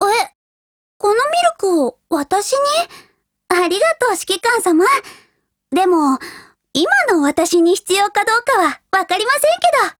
贡献 ） 协议：Copyright，其他分类： 分类:少女前线:MP5 、 分类:语音 您不可以覆盖此文件。
MP5Mod_DIALOGUE2_JP.wav